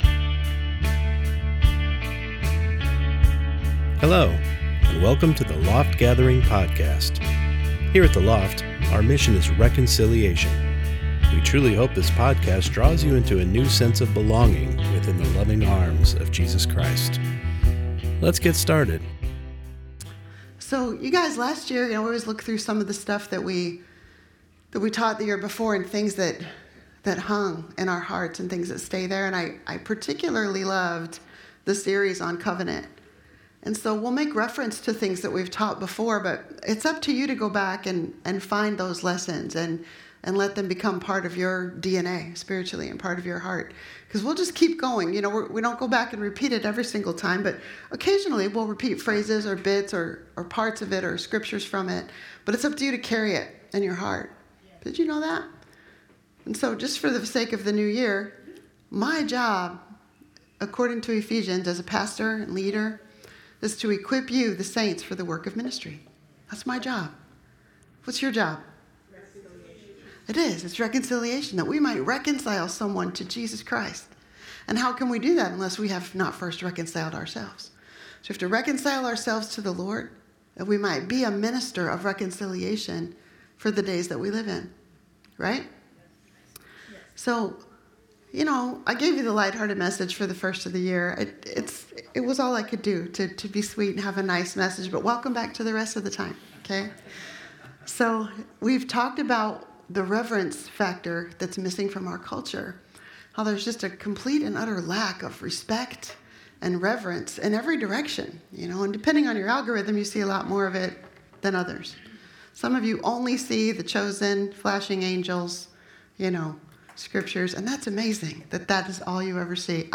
Service: Sunday Morning Service